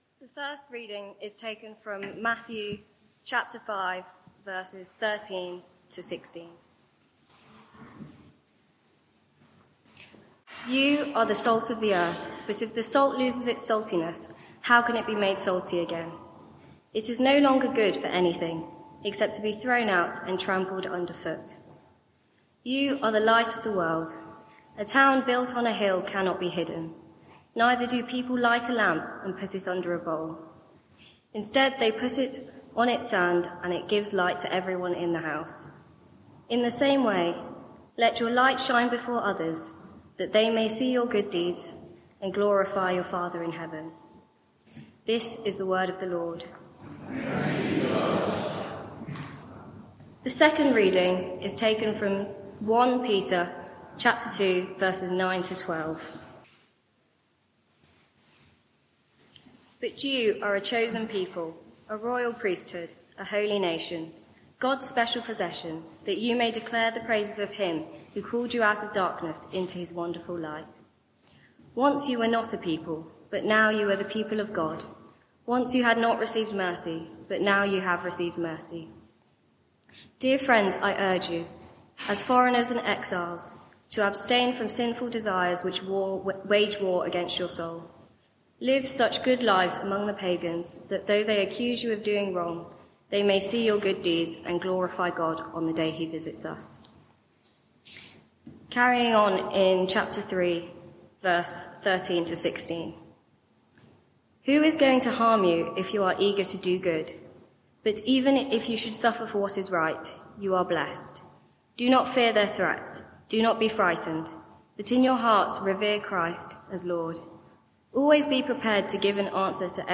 Media for 6:30pm Service on Sun 14th Jul 2024
Passage: Matthew 5:14-16, 1 Peter 2:9-12, 3:13-16 Series: Body beautiful Theme: (5) Witnessing Sermon (audio)